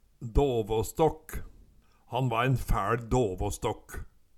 Høyr på uttala Ordklasse: Substantiv hankjønn Attende til søk